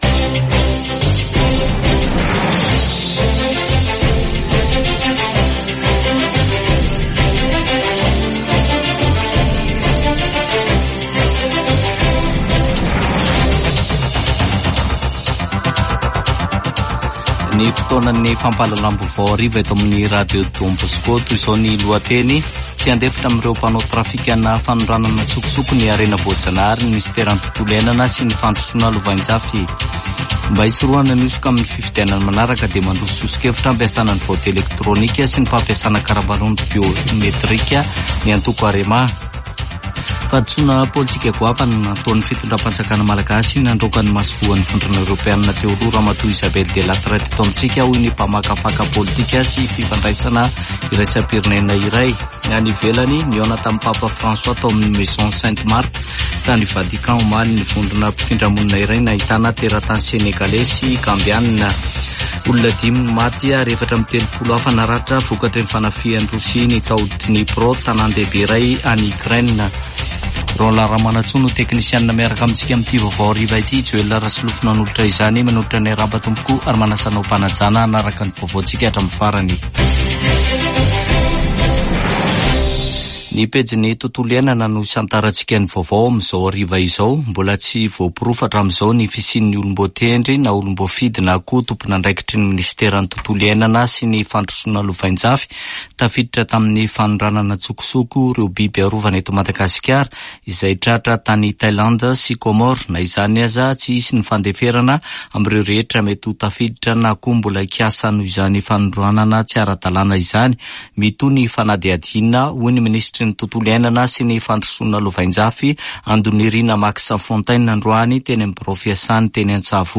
[Vaovao hariva] Alarobia 3 jolay 2024